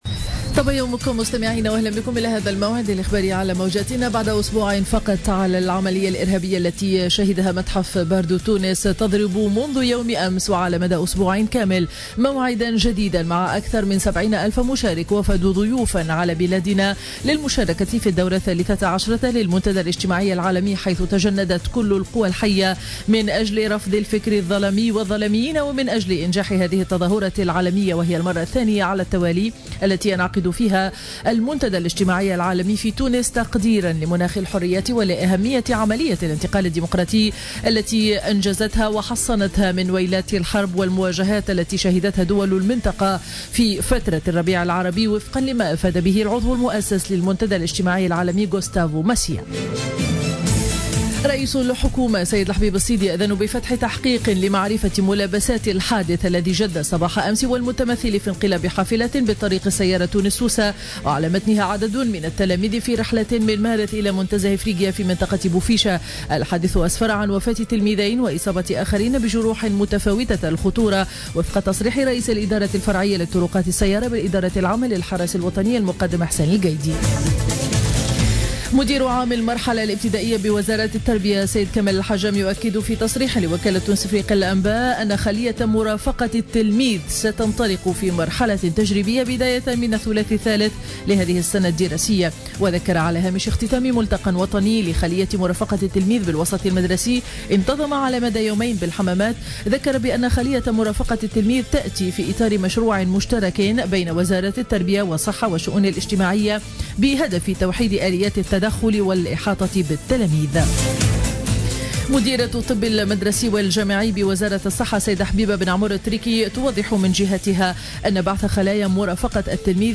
نشرة أخبار السابعة صباحا ليوم الاربعاء 25 مارس 2015